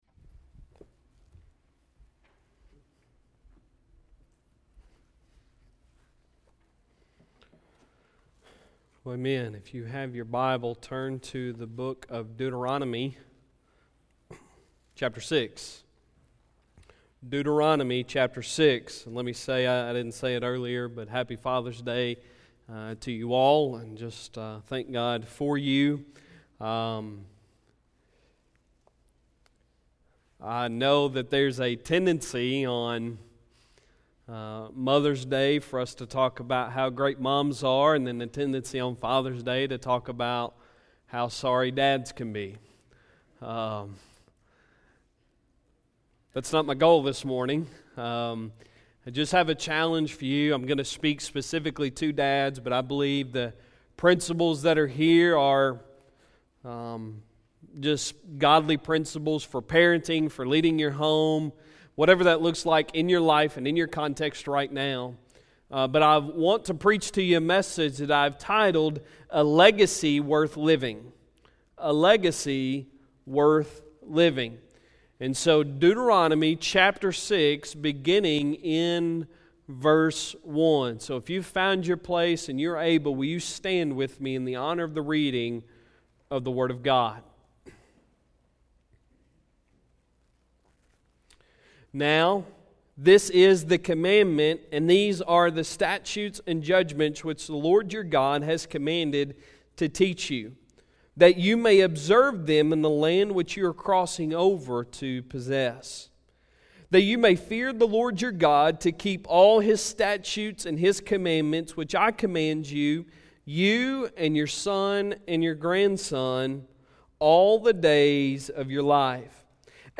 Sunday Sermon June 16, 2019